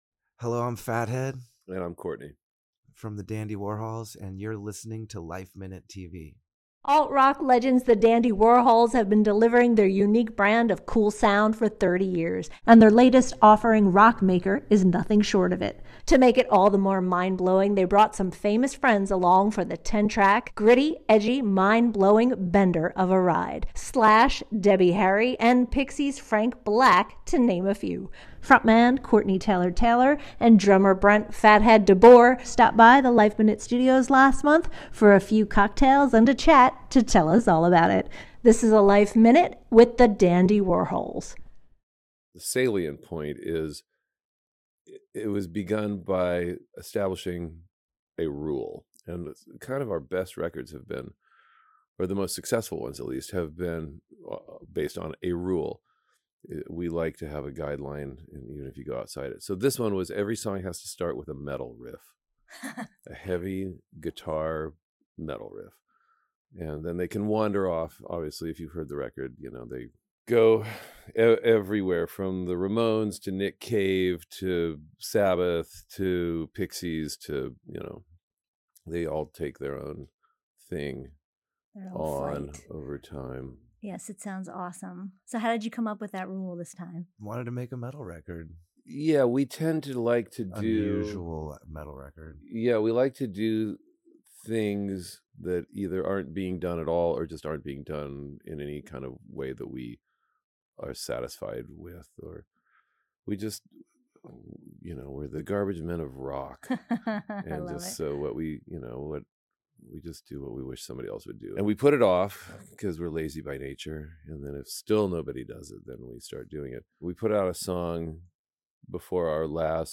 Frontman Courtney Taylor-Taylor and drummer Brent “Fathead” DeBoer stopped by the LifeMinute Studios last month for a few cocktails and a chat to tell us all about it.